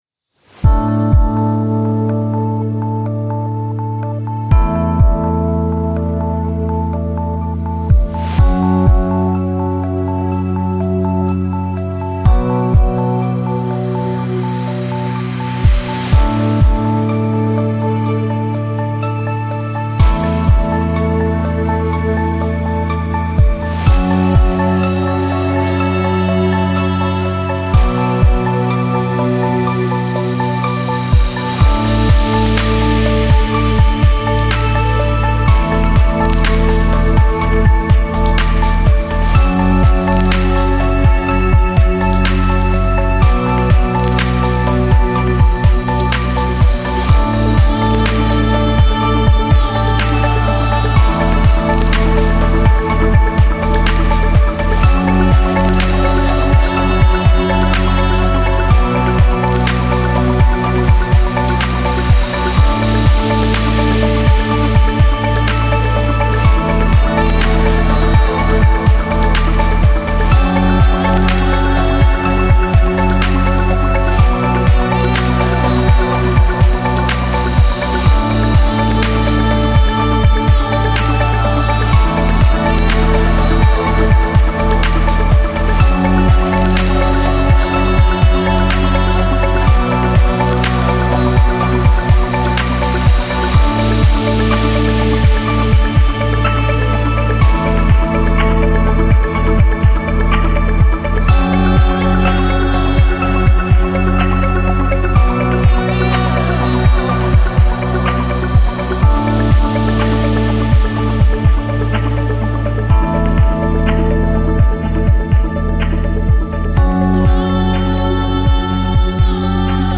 7 - Light and Calm Inspiring